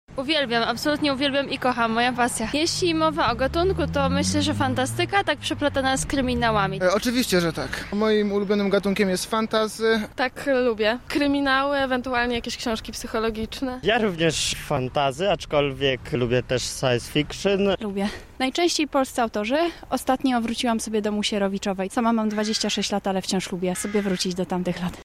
Na pytanie czy lubią czytać mieszkańcy Lublina odpowiadają krótko – tak.